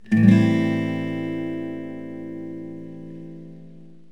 Am7.mp3